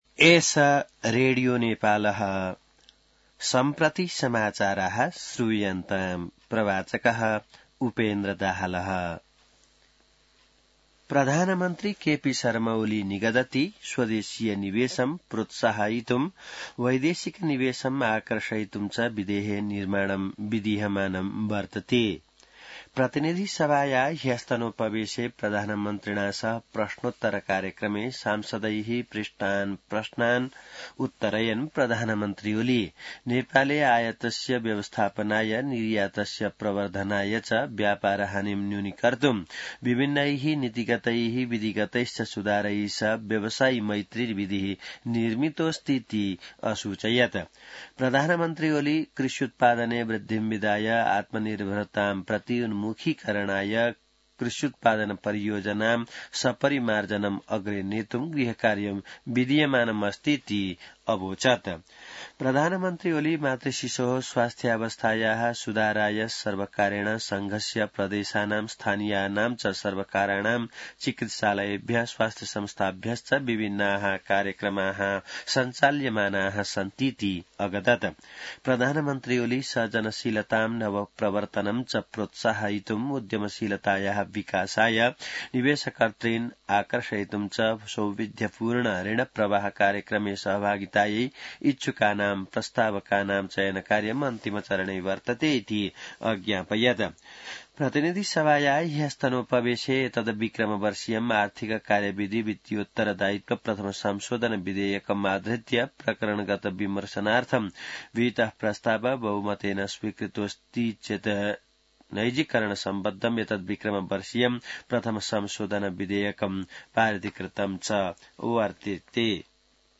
संस्कृत समाचार : ८ चैत , २०८१